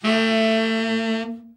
Index of /90_sSampleCDs/Giga Samples Collection/Sax/TENOR OVERBL
TENOR OB   4.wav